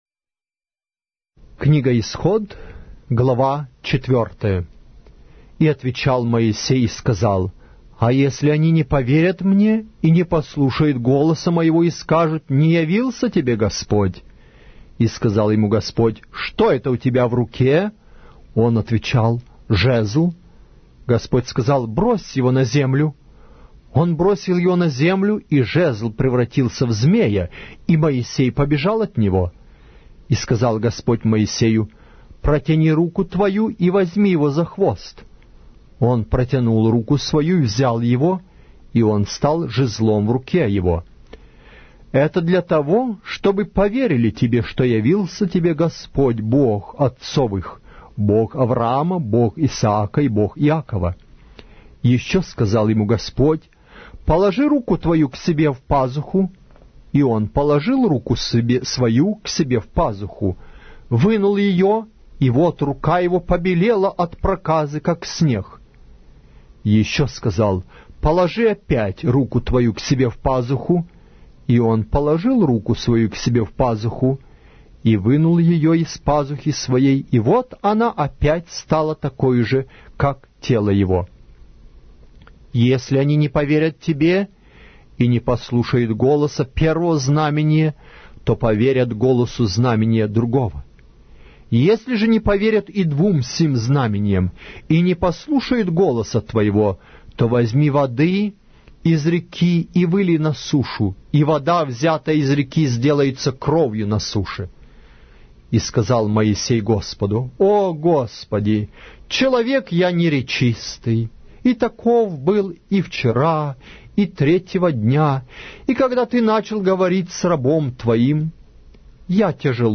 Аудиокнига: Книга 2-я Моисея. Исход